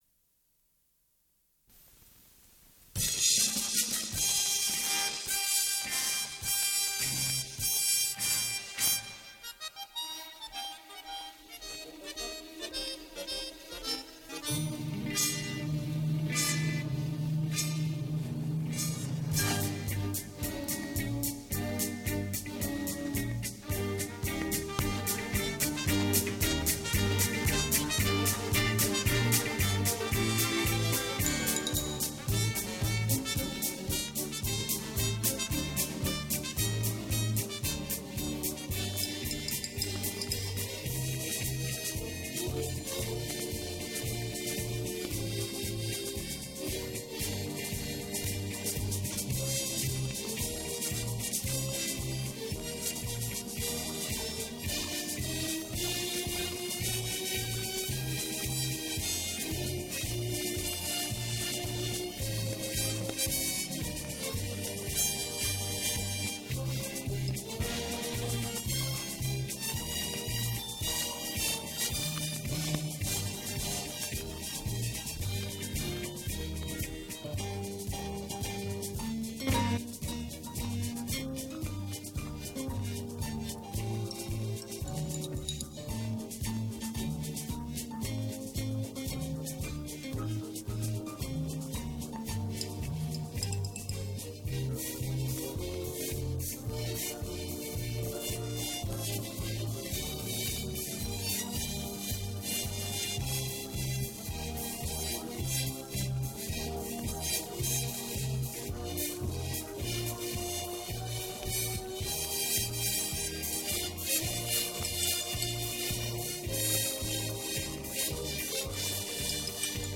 Дубль  моно.